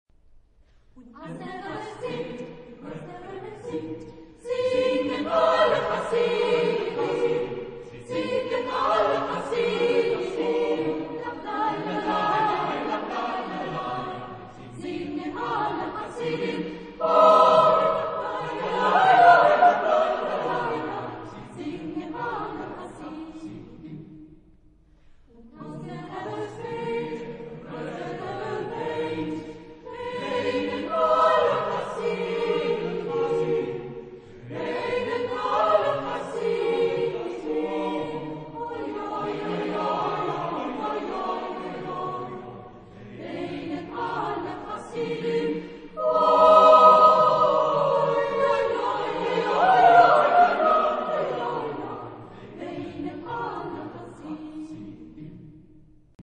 Genre-Style-Form: Folk music ; Partsong ; Secular
Type of Choir: SATB  (4 mixed voices )
Tonality: E minor
Origin: Eastern Europe
sung by Chorus Cantemus Naumburg